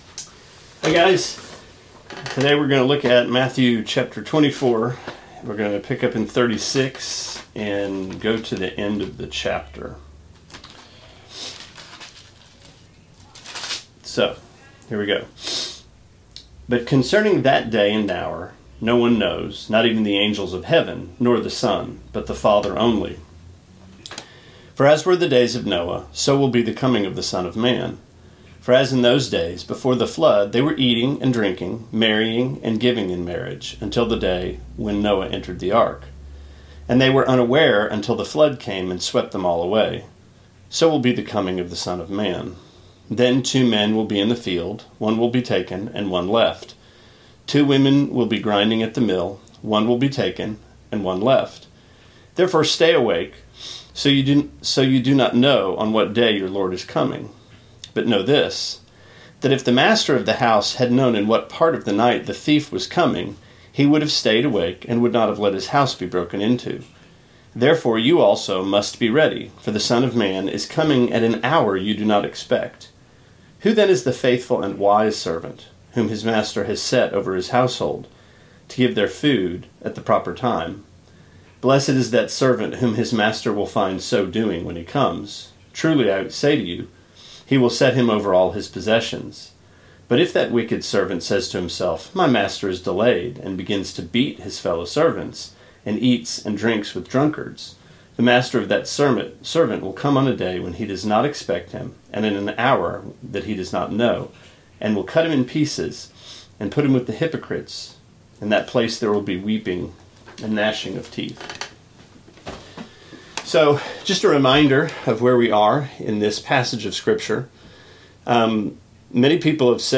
Sermonette 4/1: Matthew 24:36-51